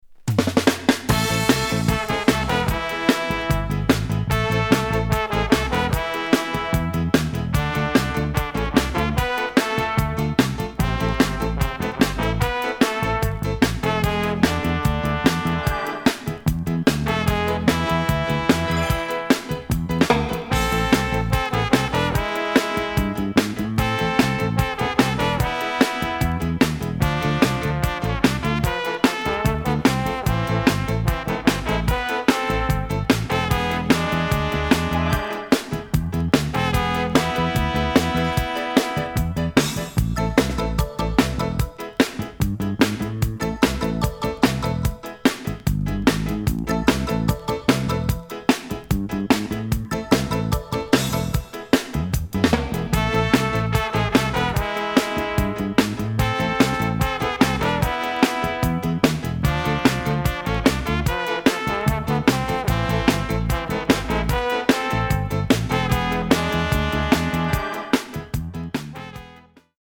レゲエ・カバー